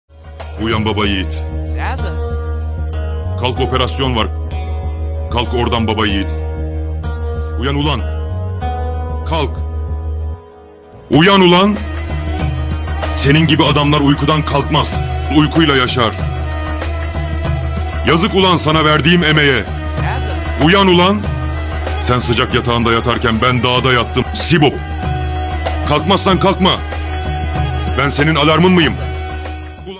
Pala Alarm Sesi
Kategori: Zil Sesleri
pala-alarm-sesi-tr-www_tiengdong_com.mp3